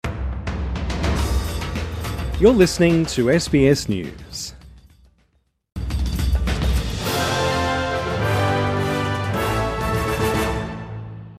Midday News Bulletin 30 August 2024